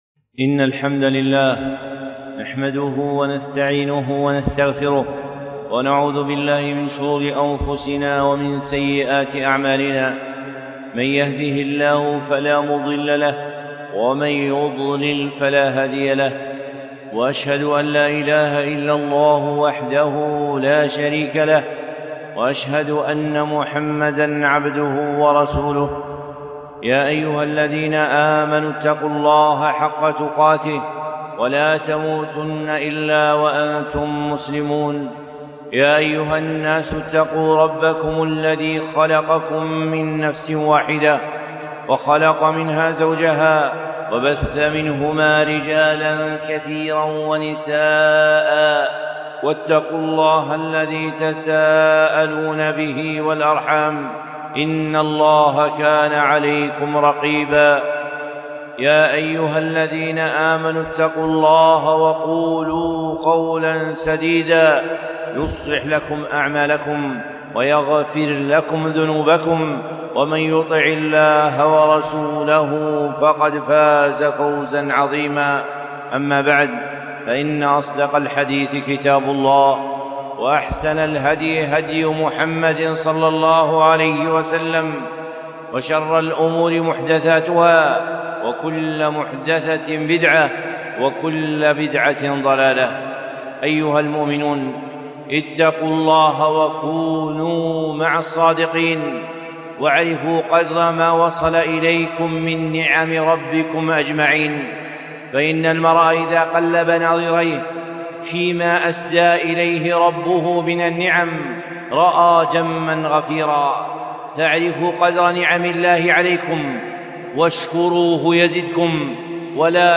خطبة - الصدق طريق الجنة